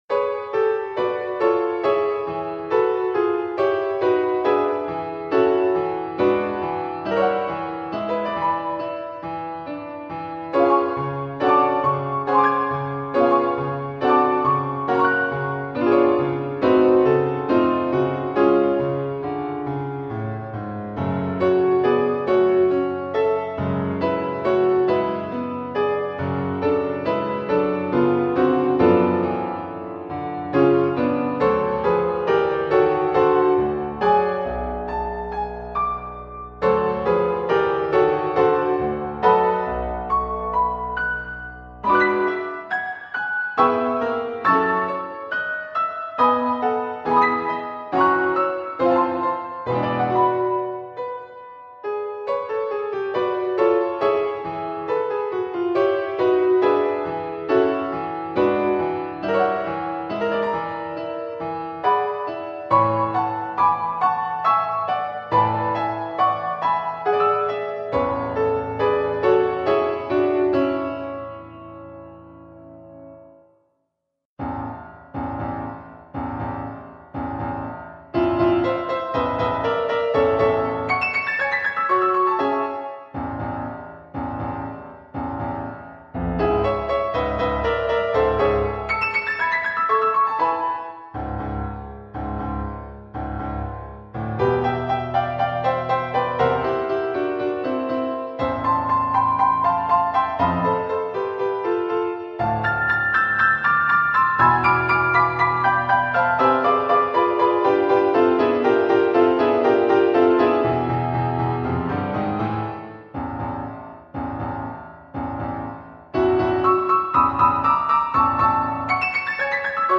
Andantino grazioso
Allegro non troppo
Larghetto